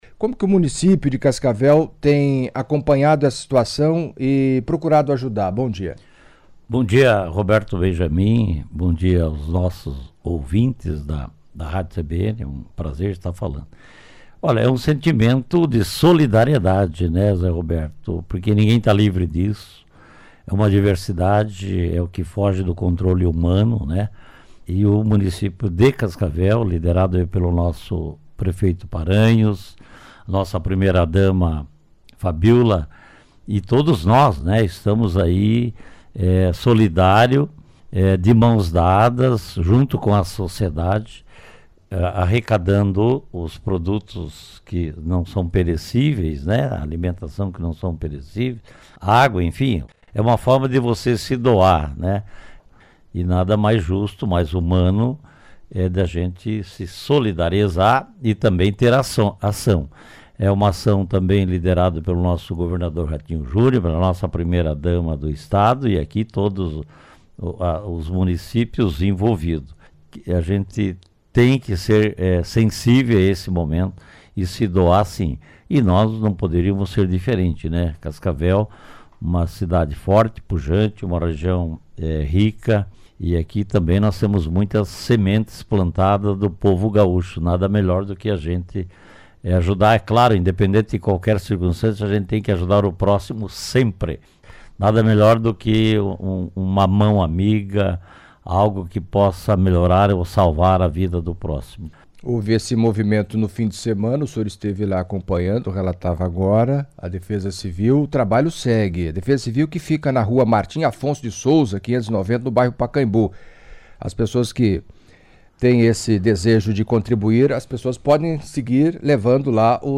Em entrevista à CBN nesta segunda-feira (06), Renato Silva, vice-prefeito de Cascavel, falou da ajuda humanitária do município ao povo do Rio Grande Sul, castigado pelas intensas chuvas.